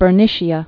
(bər-nĭshē-ə, -nĭshə, bĕr-)